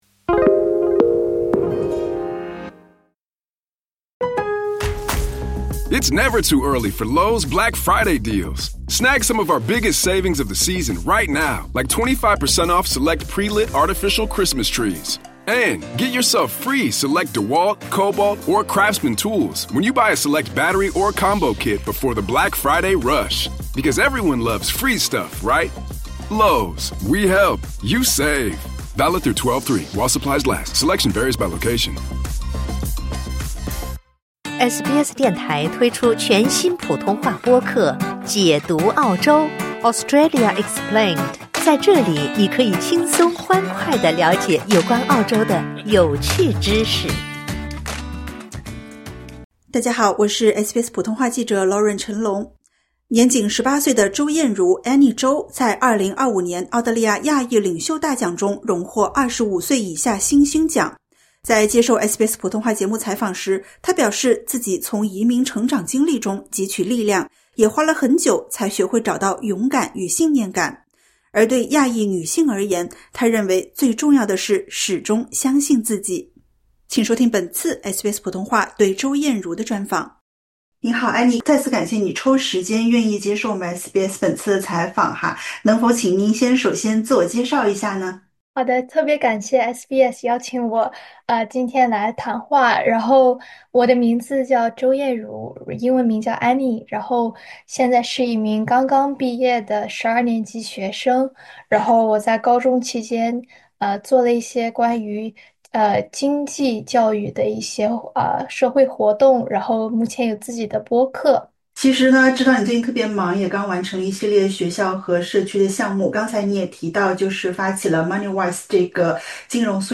在接受SBS普通话节目采访时，她表示自己从移民成长经历中汲取力量，也花了很久才学会找到“勇敢与信念感”。而对亚裔女性而言，她认为最重要的是——始终相信自己。